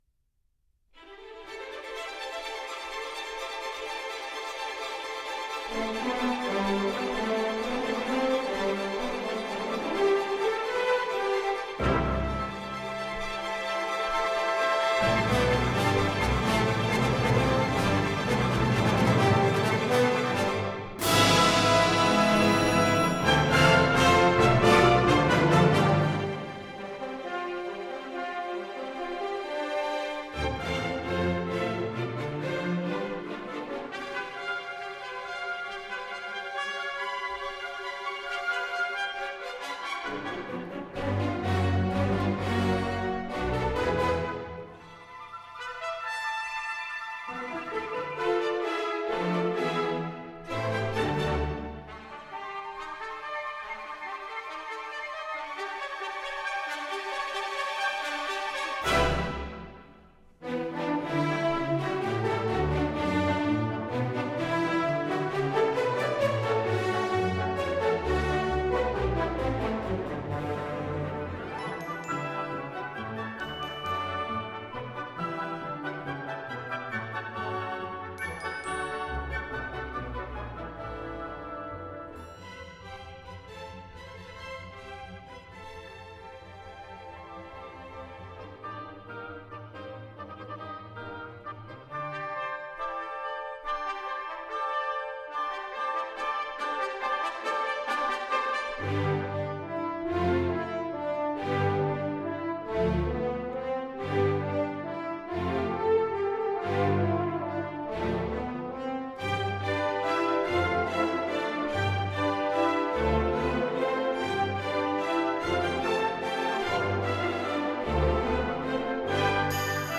Wikipedia Music: Gustav Holst, "Jupiter," from The Planets https